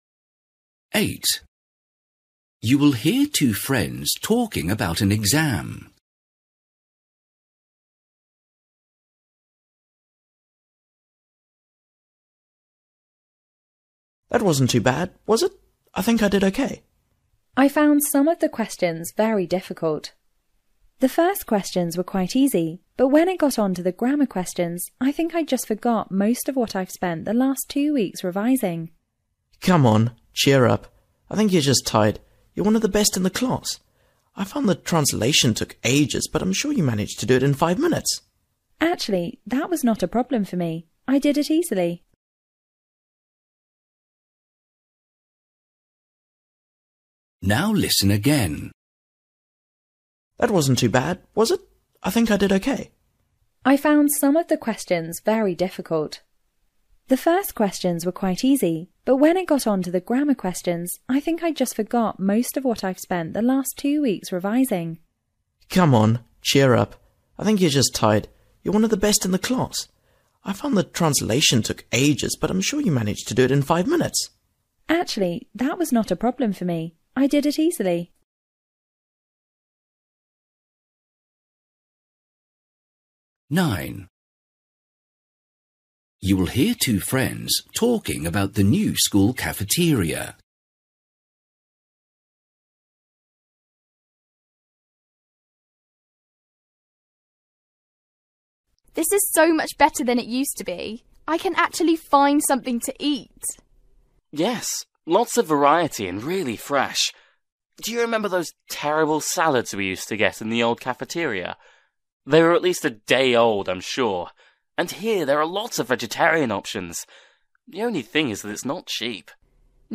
Listening: everyday short conversations
8   You will hear two friends talking about an exam. The boy advises the girl to
10   You will hear a girl talking about her weekend. How did she feel about the weather?